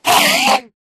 scream3.ogg